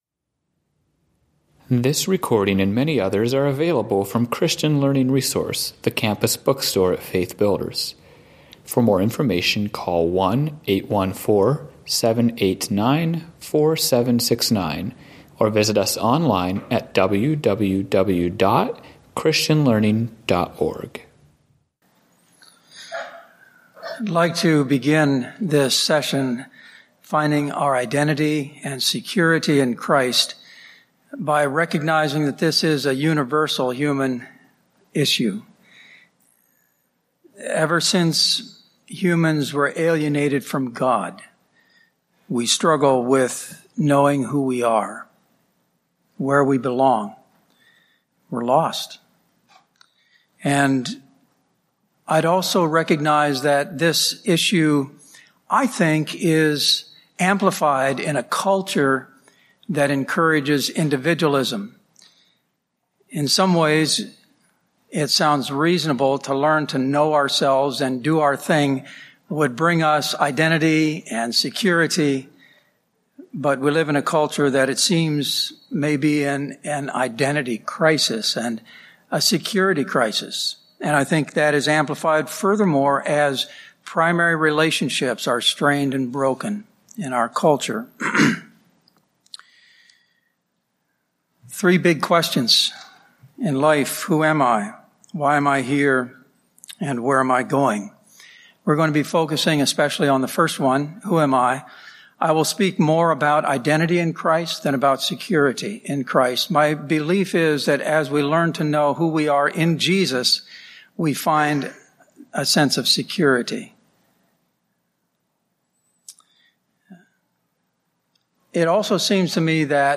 Home » Lectures » Finding Identity and Security in Christ